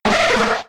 Cri de Nidoran♀ K.O. dans Pokémon X et Y.